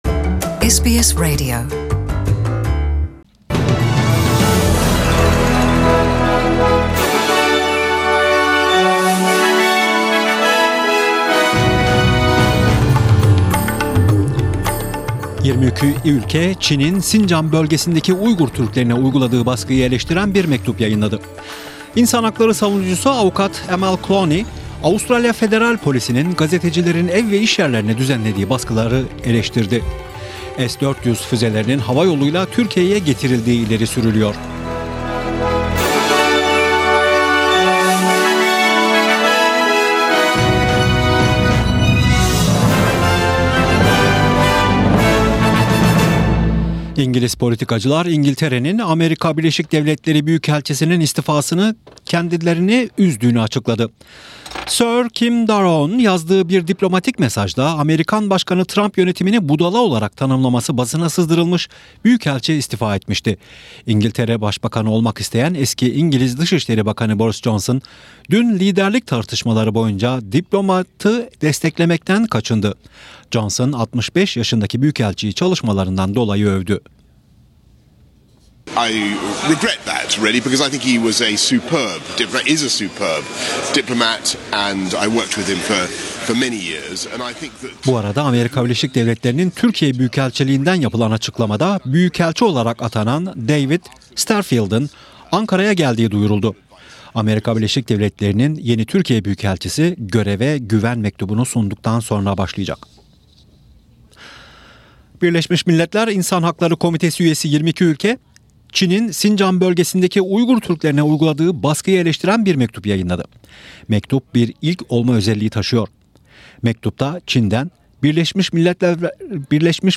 SBS Radyosu Türkçe Programı'nda Avustralya, Türkiye ve dünyadan haberler. Başlıklar: **Birleşmiş Milletler İnsan Hakları Komitesi üyesi 22 ülke, Çin'in Sincan bölgesindeki Uygur Türklerine uyguladığı baskıyı eleştiren bir mektup yayınladı.